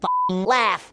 F***ing Laugh!